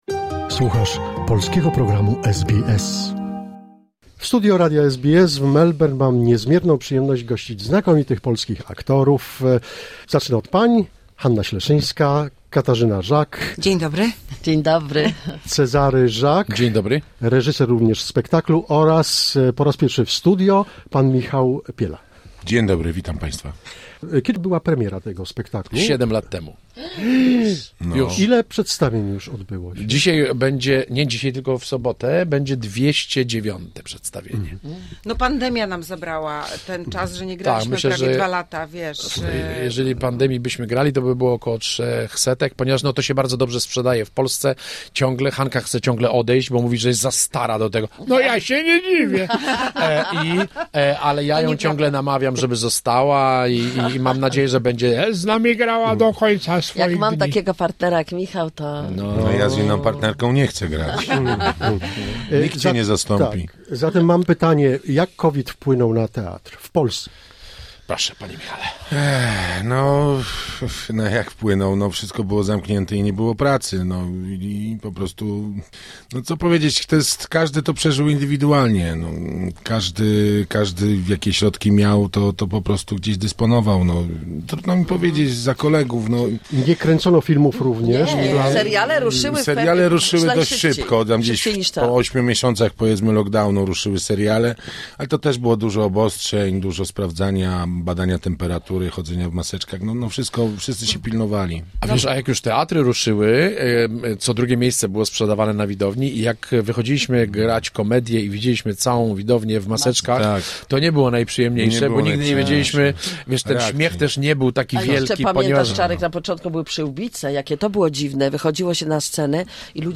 Ciąg dalszy rozmowy ze znakomitymi aktorami komediowymi. Cezary Żak, Katarzyna Żak, Hanna Śleszyńska, Michał Piela odwiedzili studio SBS w Melbourne. Aktorzy występują w Australii w komedii 'Serca na Odwyku'.